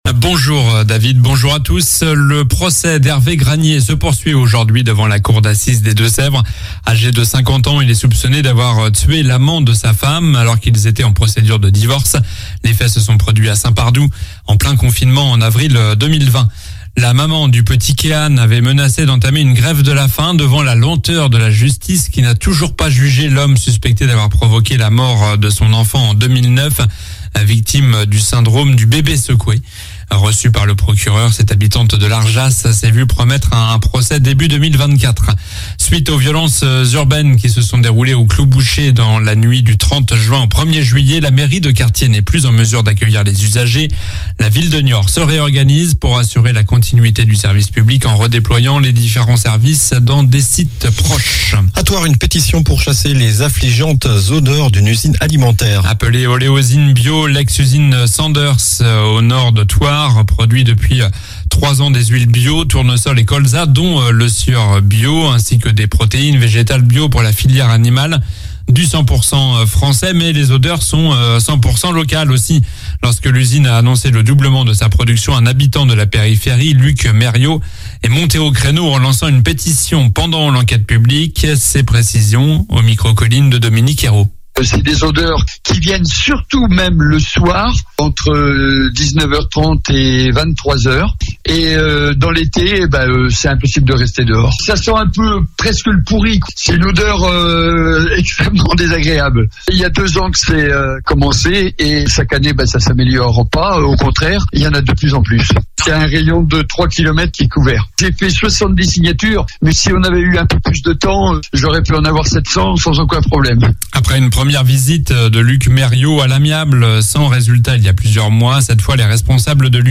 Journal du vendredi 07 juillet (midi)